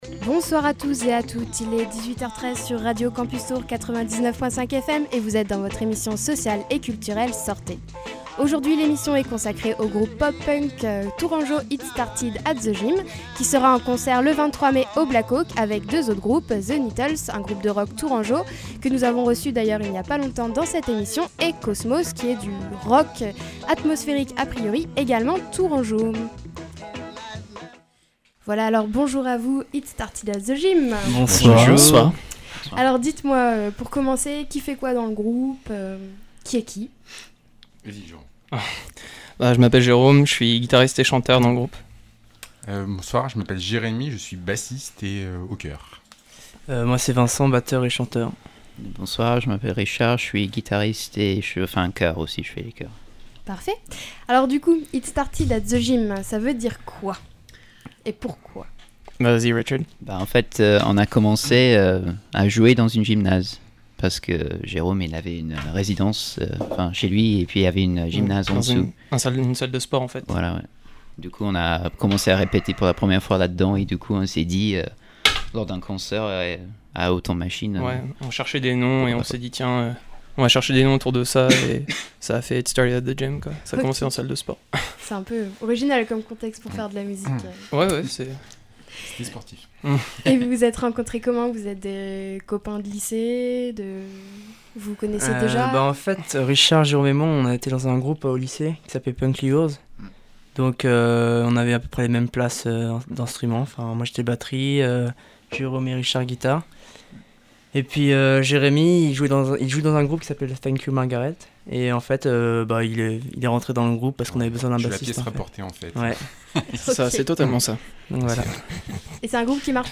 It Started At The Gym est un groupe pop punk tourangeau.
Sortez! a invité les quatre membres du groupe. Au cours de cet interview It Started At The gym, nous présente leur musique, leurs projets et nous font profiter d’un live en direct des studios de Radio Campus Tours!